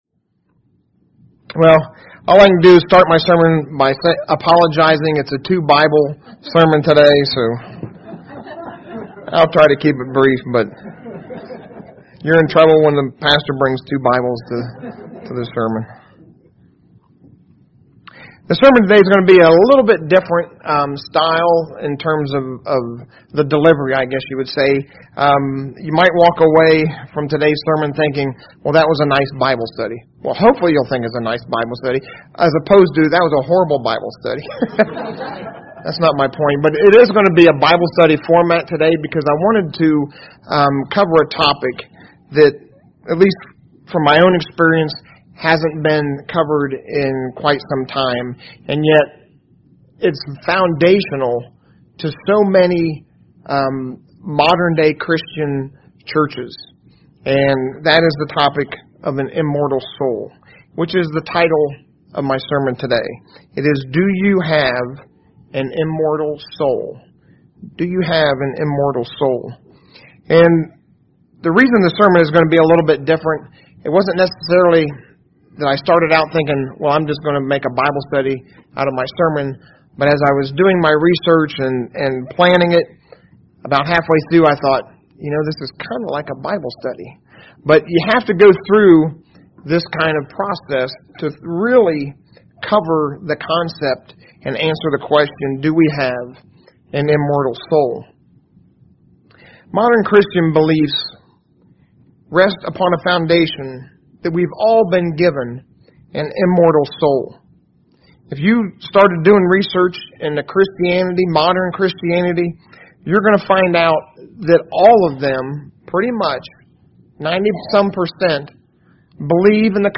UCG Sermon Notes Notes: Do we have an immortal soul?